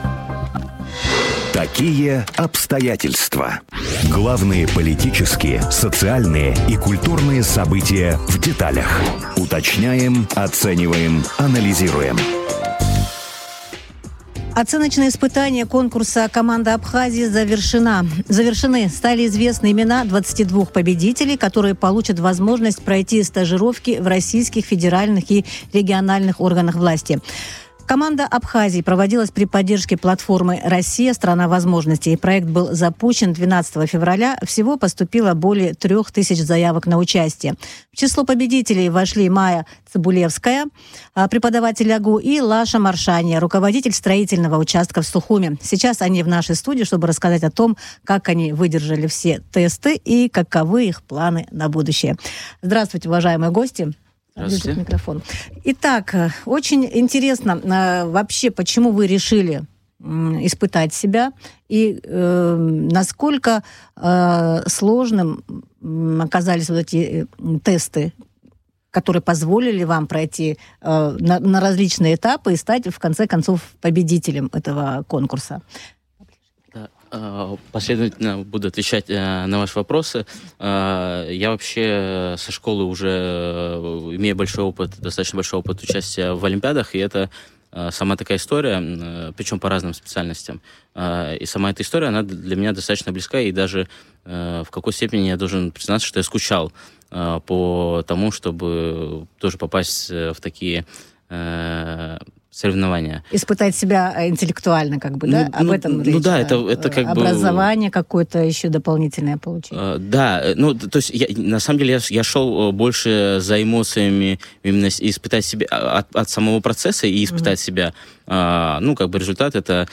Сейчас они в нашей студии, чтобы рассказать о том, как они выдержали все тесты и каковы их планы на будущее